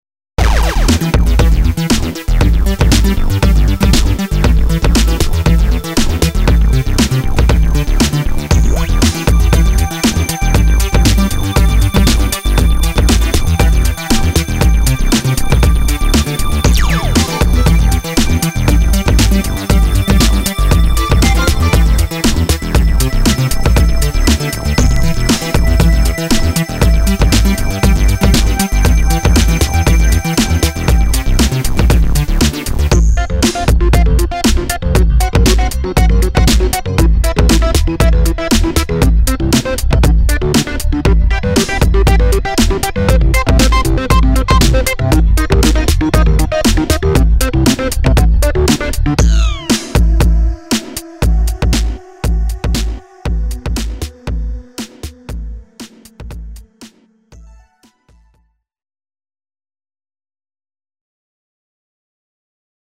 —Synthesizers triggered by guitars
—Guitars played through synths
—Sonics that reverberate from within the earth
—Pulsations to make you move your hips